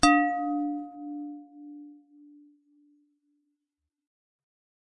高音玻璃碗" 手指打高玻璃碗1
描述：手指轻拍高倾斜的玻璃碗。用Zoom Q3HD录制。使用Reaper的ReaFir插件降低噪音。
标签： 命中 手指 玻璃 抽头
声道立体声